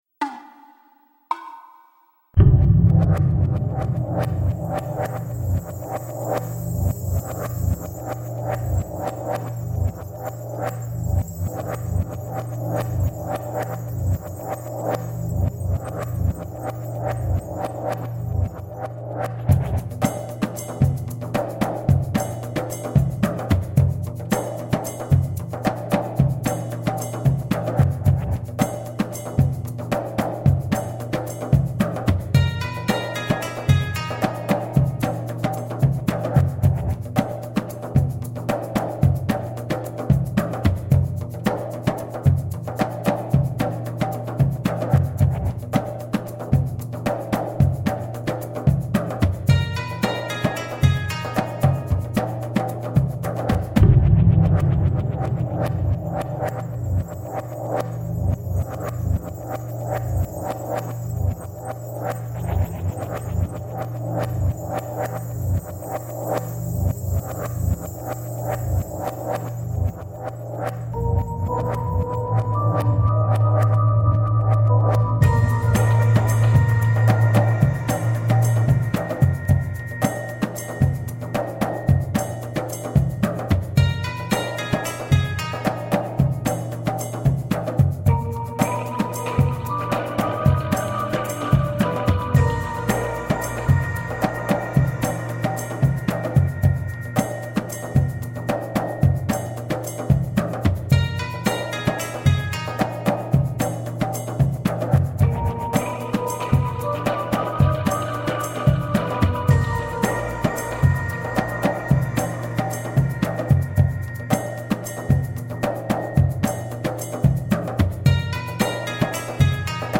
Passionate eastern percussion.
all of solo percussion.
Tagged as: World, Other, Arabic influenced